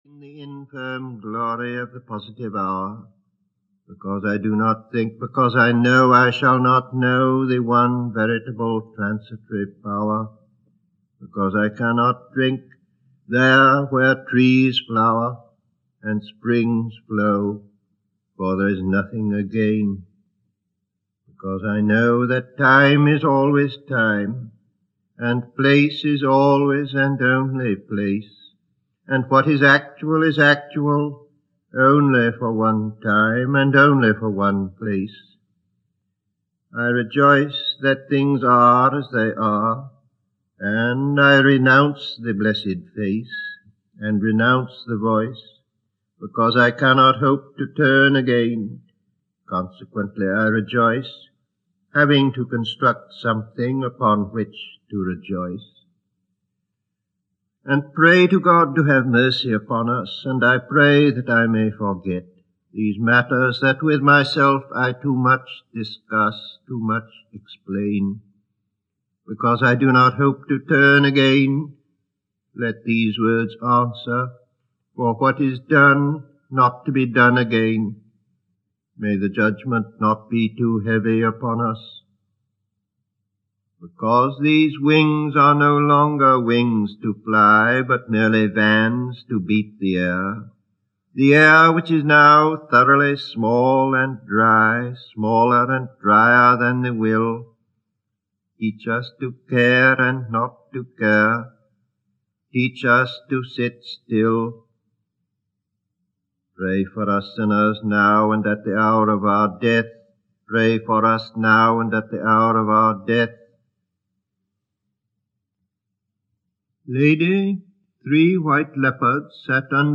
Audiobook T.S. Eliot Reading his Poems and choruses. A selection of poems and choruses read by T.S. Eliot himself, recorded in 1959.